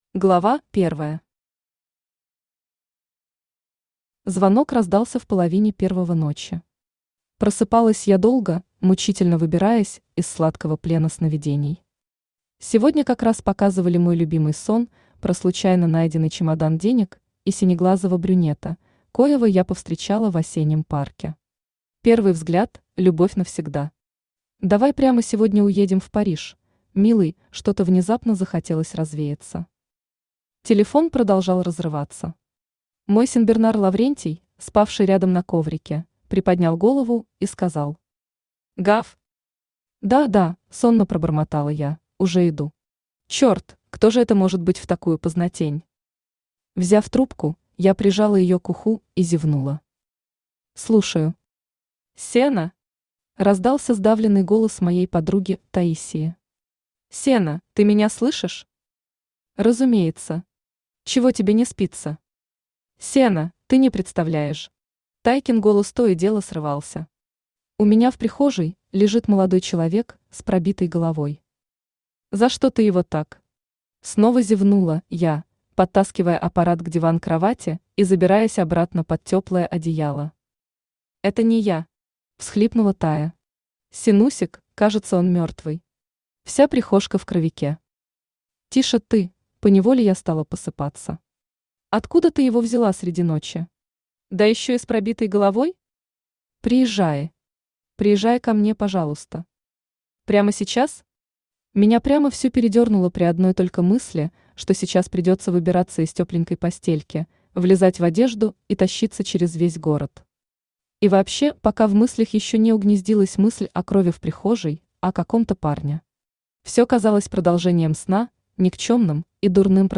Aудиокнига Звезда тантрического секса Автор Галина Полынская Читает аудиокнигу Авточтец ЛитРес.